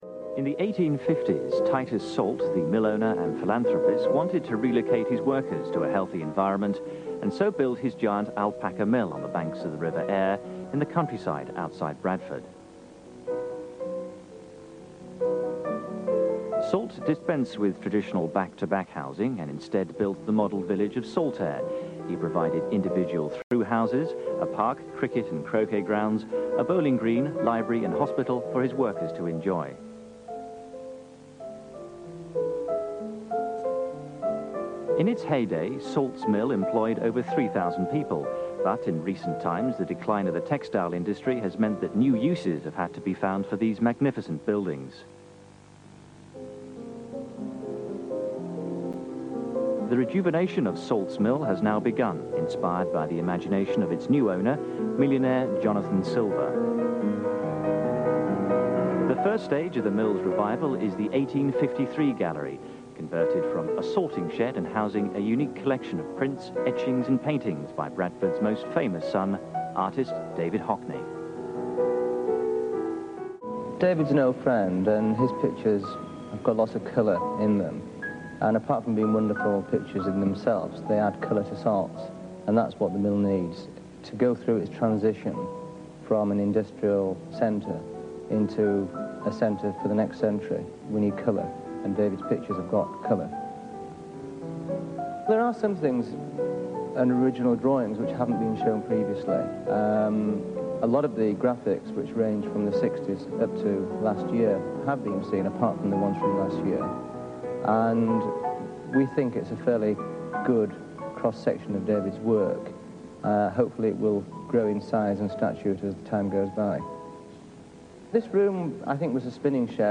1987 interview